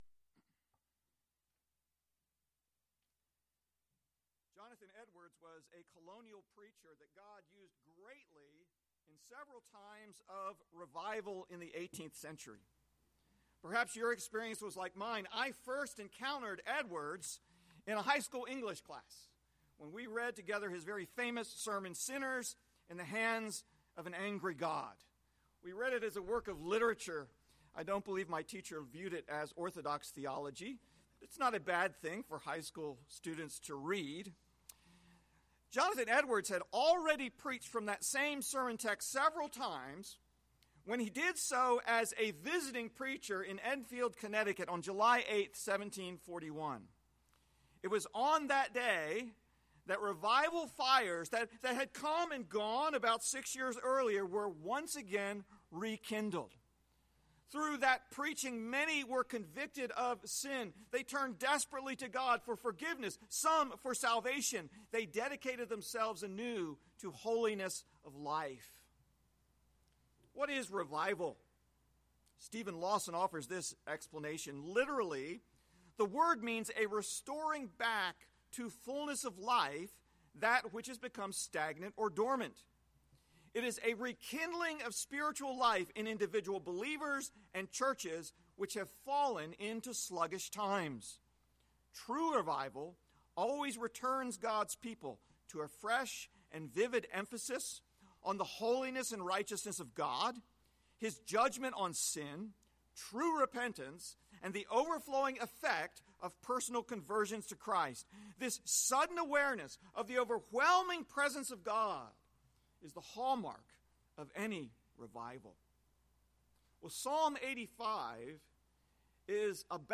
Message Text: Psalm 85:1-13